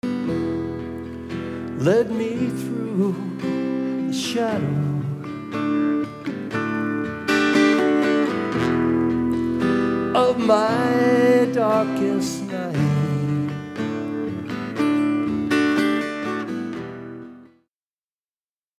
Original Song from Fundraiser Concert 2016